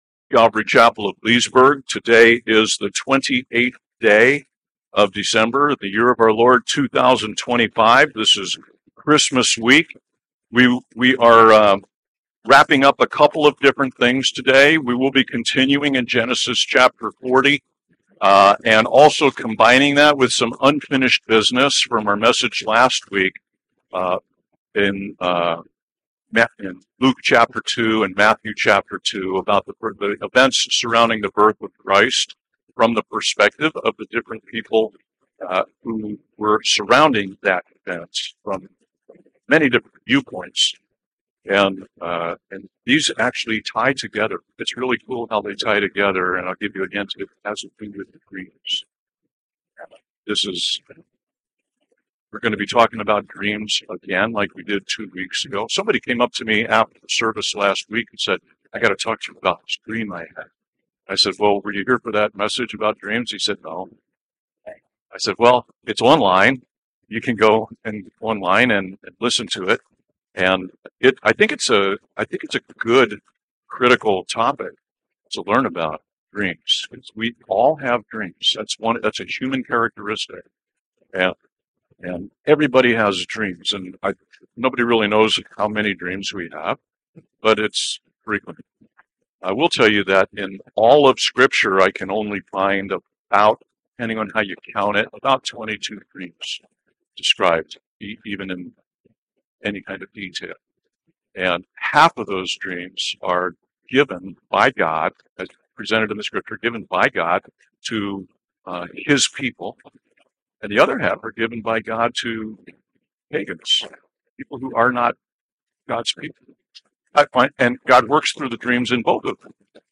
by Calvary Chapel Leesburg | Dec 28, 2025 | Sermons